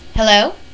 femaleh3.mp3